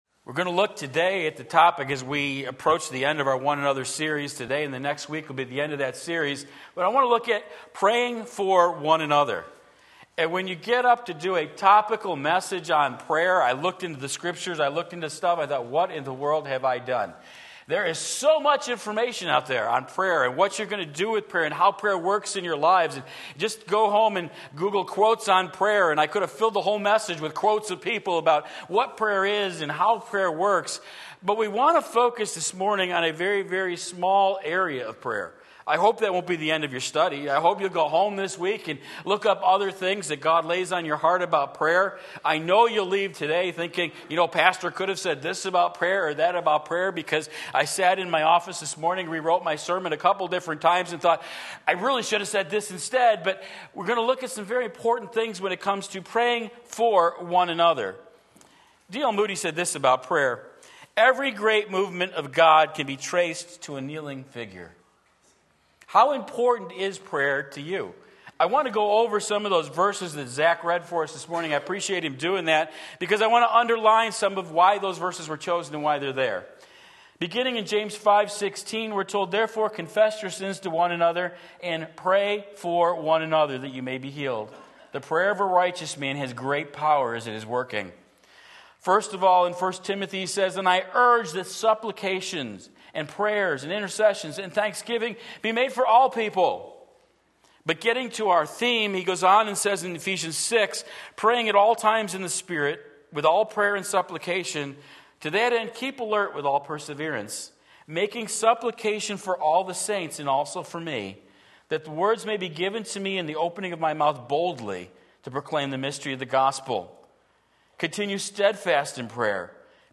Sermon Link
Pray for One Another James 5:16 Sunday Morning Service